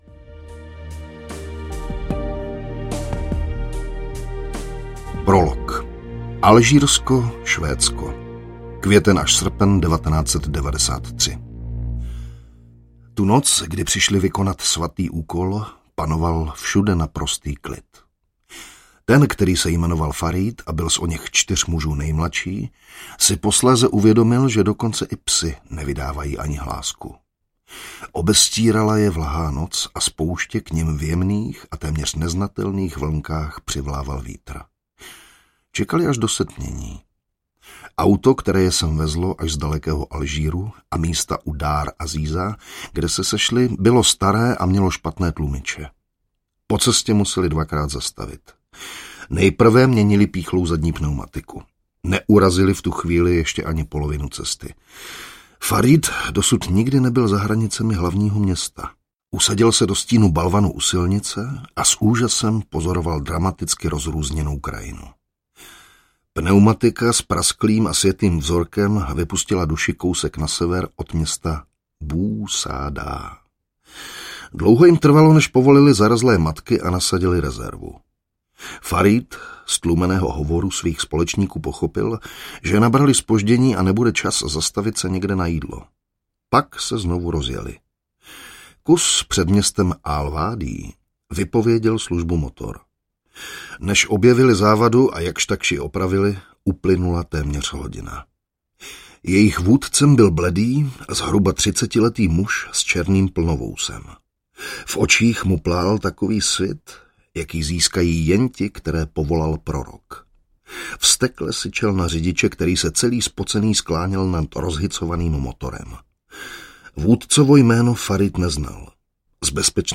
Pátá žena audiokniha
Ukázka z knihy